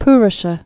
(prə-shə)